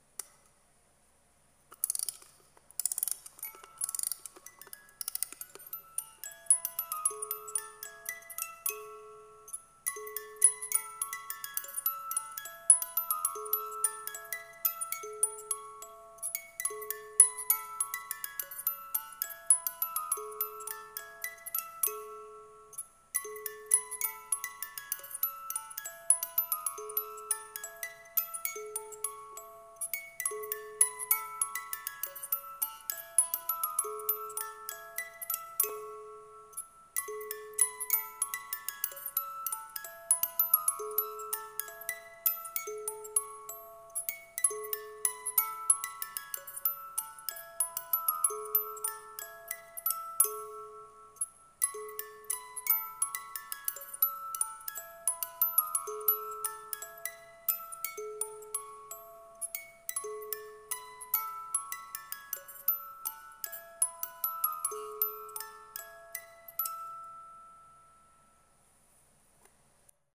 I found a music box I have had since I was a baby, it's old and a bit ruined but the music still plays just how I remember it. However, I was curious on what song it's playing since I've never heard it elsewhere.